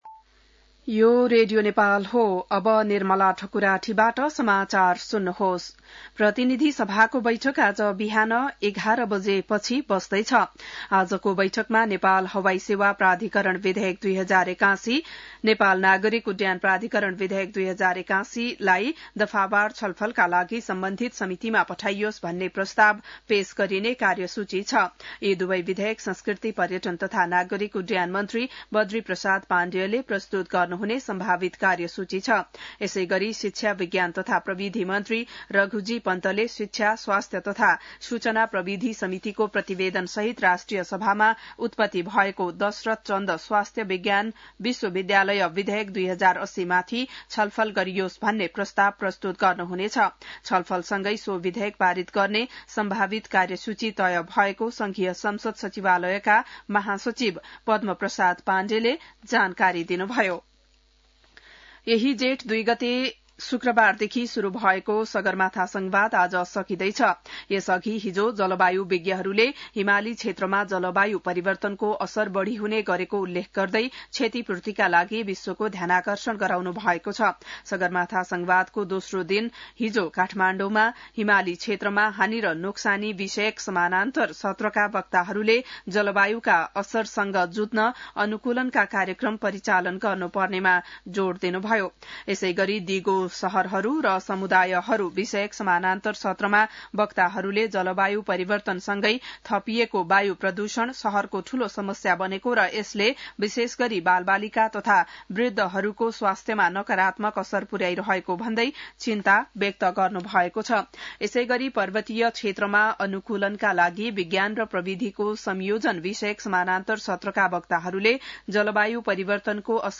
बिहान १० बजेको नेपाली समाचार : ४ जेठ , २०८२